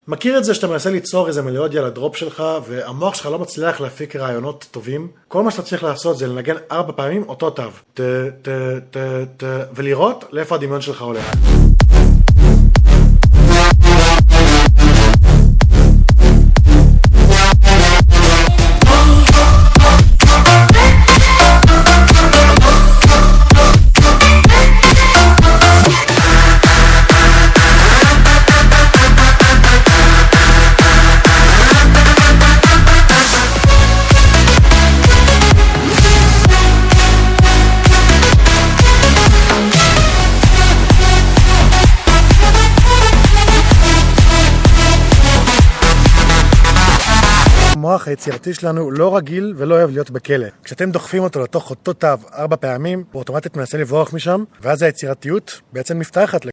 כל מה שאתם צריכים לעשות הוא לנגן 4 פעמים ברצף אותו תו, ולראות לאן הדמיון שלכם לוקח אתכם.
(יש דרופ אחד שטיפה נחתך, אבל תסמכו עלי שגם הוא מתחיל ב"טה טה טה טה" ) טריק ה_טה טה טה טה_ (עריכה מהירה מהפלאפון אז תסלחו לי על התיתוכים) _מוזיקה _הפקה _EDM(MP3_16.mp3